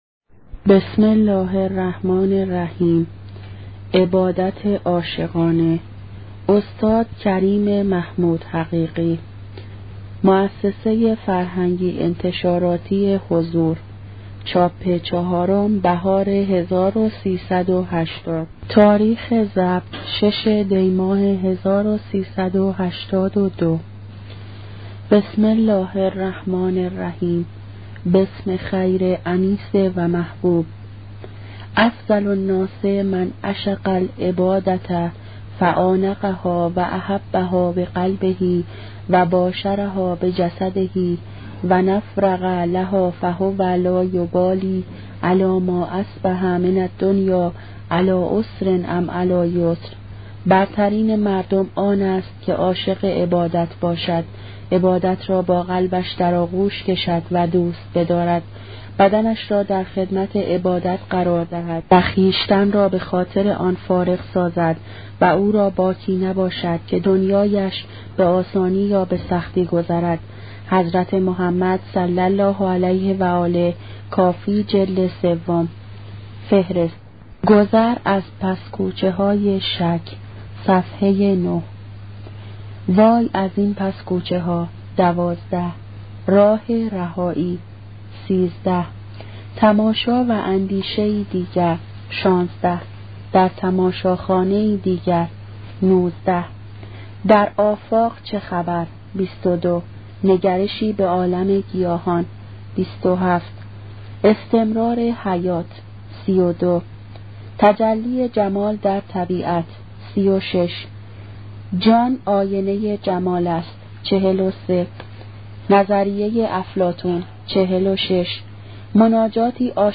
کتاب صوتی عبادت عاشقانه , قسمت اول